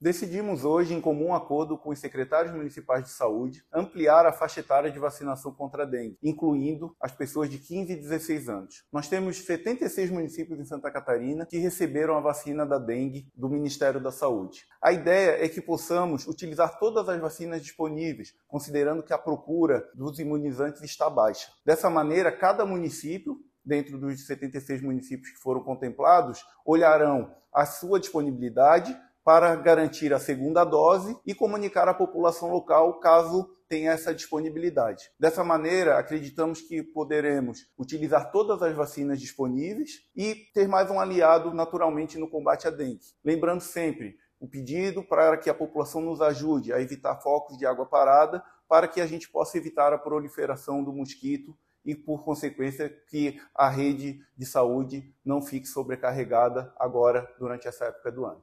SECOM-Sonora-Sec-Saude-Vacina-Contra-a-Dengue.mp3